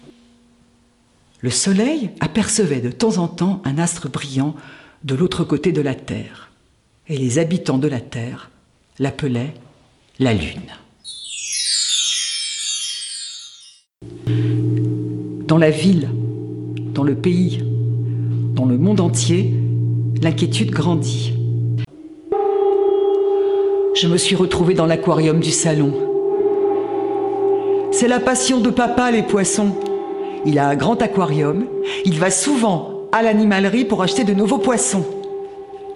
Bandes-son
extraits contes